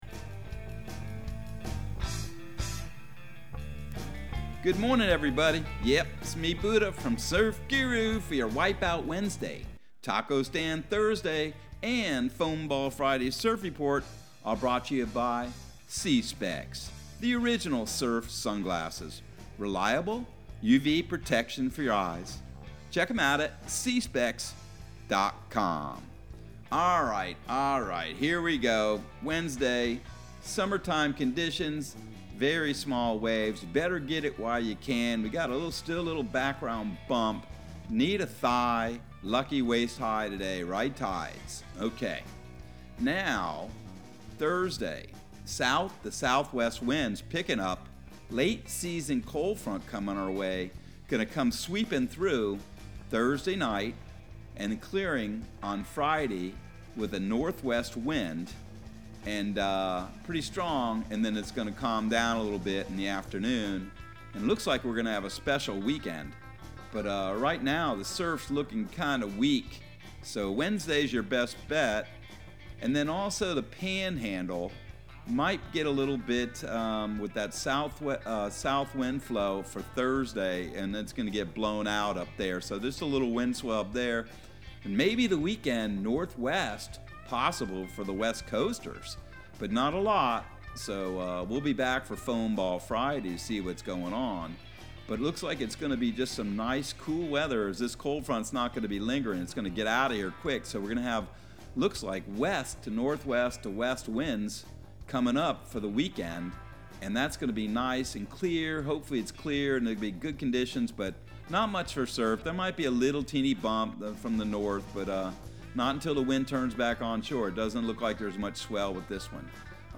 Surf Guru Surf Report and Forecast 04/06/2022 Audio surf report and surf forecast on April 06 for Central Florida and the Southeast.